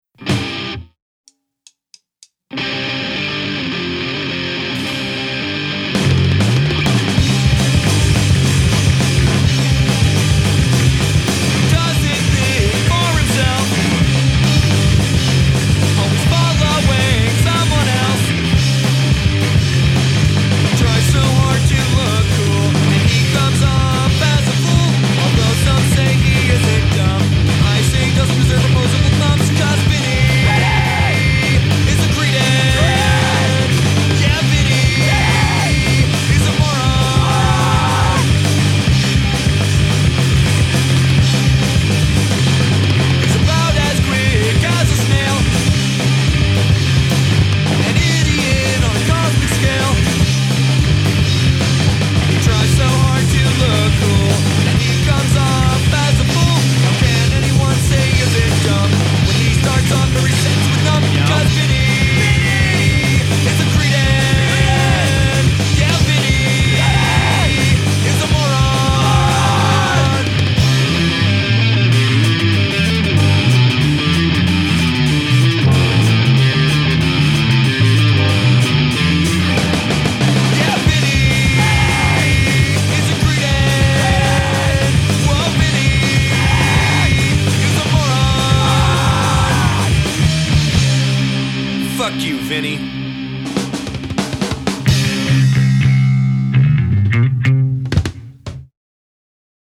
We played fast, snotty punk.
provided the rhythm section -- bass and drums.